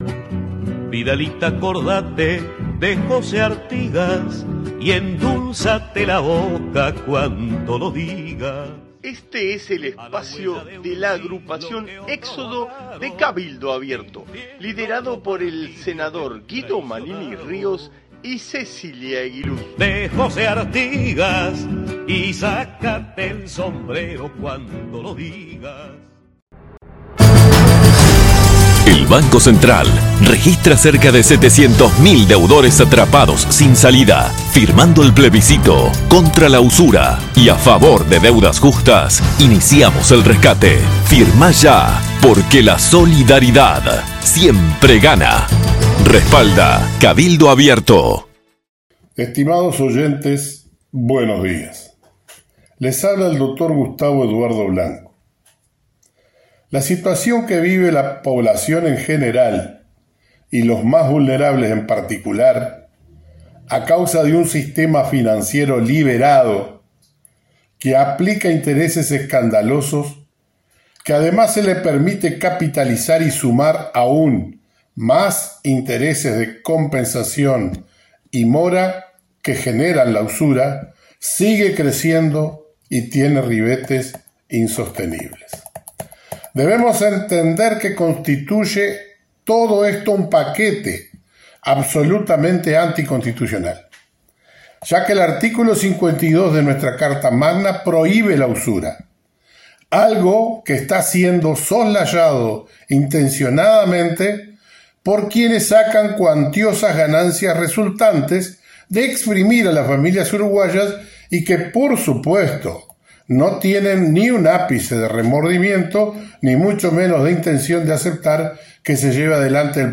Audición radial de nuestra agrupación para Radio Salto(1120AM) del día 7 de noviembre de 2023.